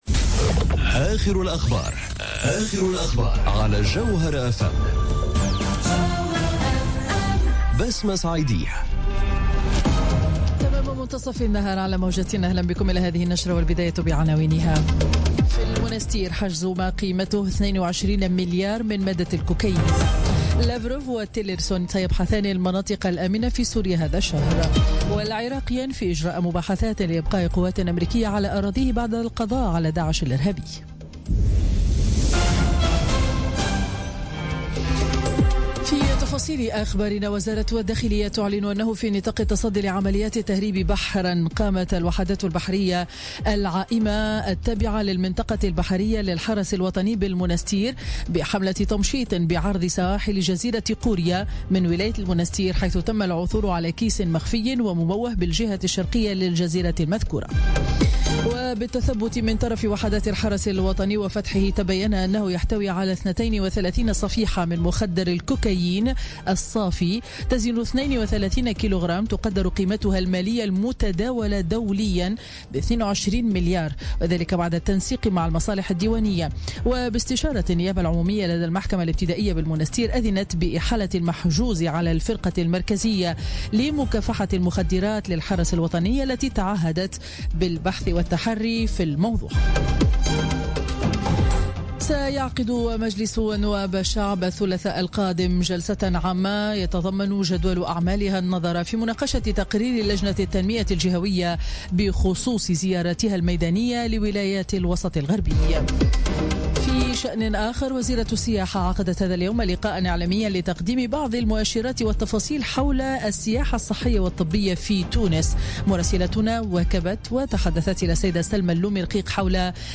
نشرة أخبار منتصف النهار ليوم الجمعة 5 ماي 2017